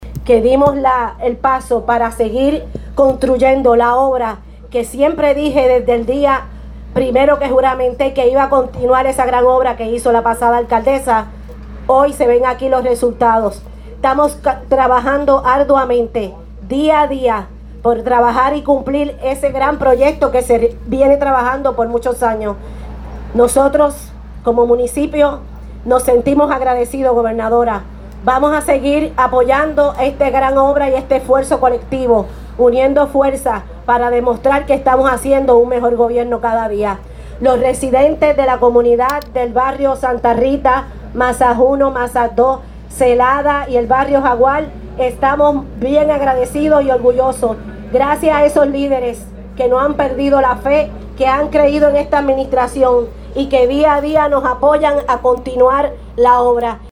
Por su parte, la alcaldesa de Gurabo Vimarie Peña Dávila reconoció la importancia de este proyecto en la vida de tanto los residentes como los visitantes.